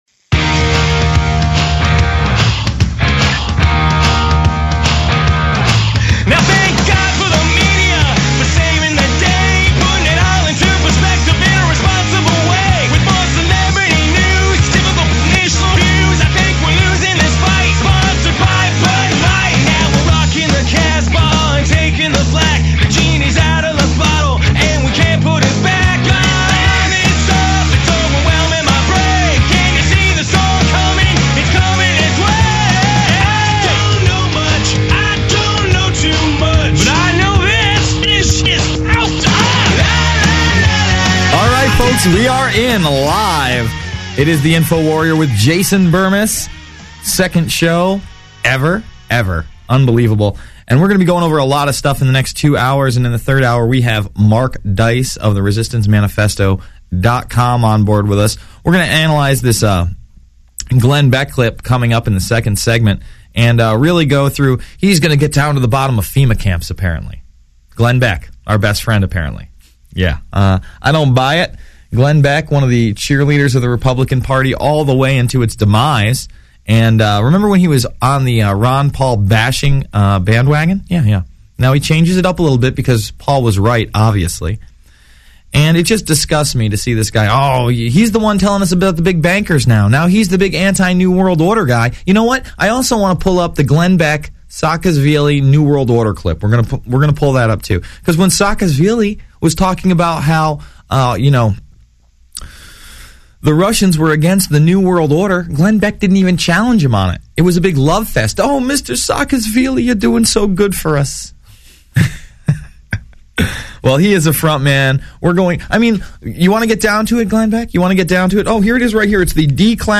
Watch Alex's live TV/Radio broadcast.